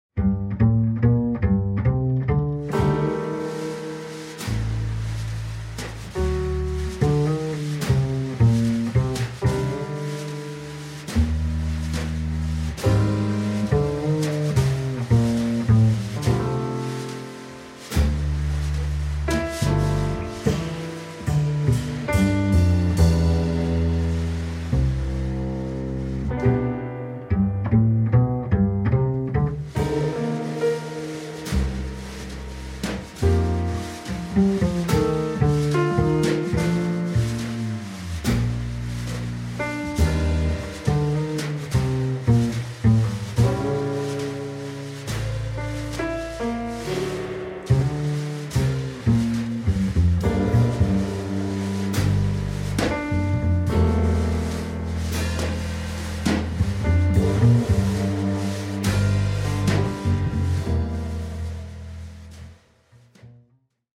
piano
batterie